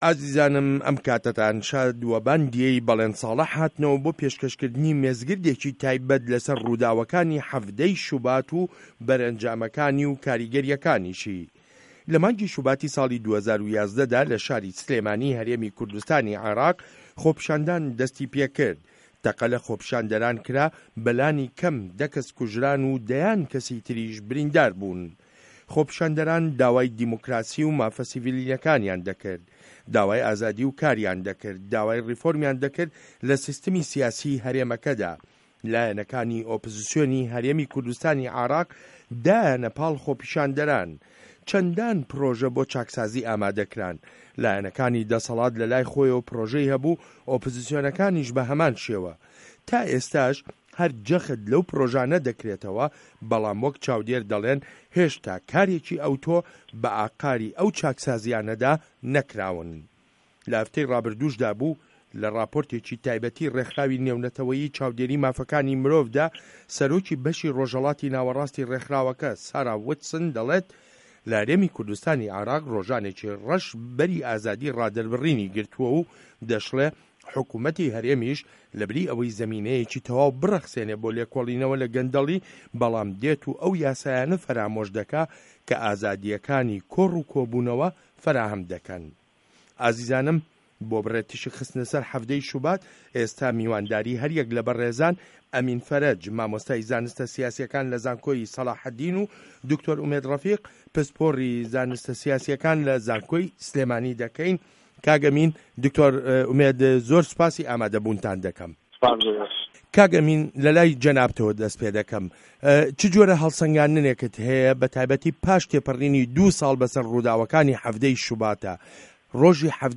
مێزگرد : 17 ی شوبات